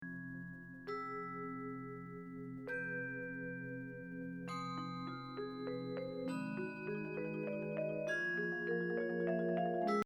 Performance Type: 3-5 Players